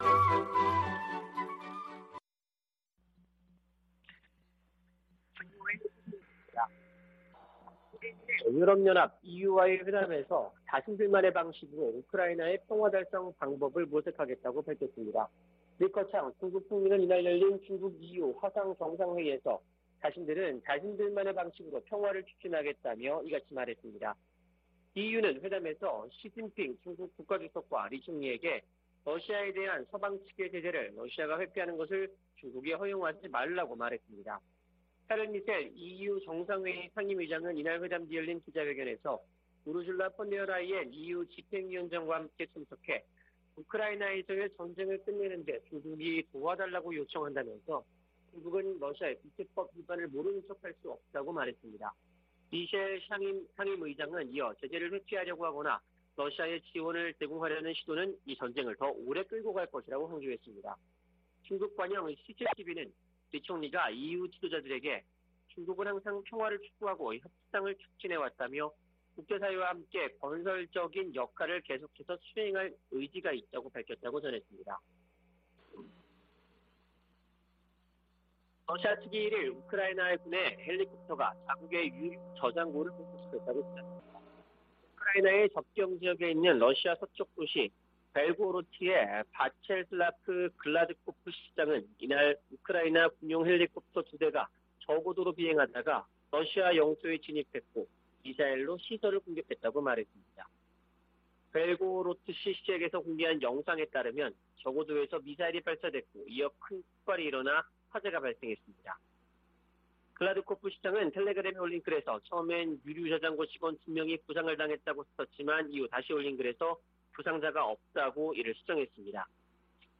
VOA 한국어 아침 뉴스 프로그램 '워싱턴 뉴스 광장' 2022년 4월 2일 방송입니다. 북한이 ICBM 발사에 이어 조기에 핵실험 도발에 나설 것이라는 전망이 나오고 있습니다. 미 국무부는 북한의 추가 도발 가능성을 주시하고 있다면서 추가 압박을 가하는 등 모든 일을 하고 있다고 강조했습니다.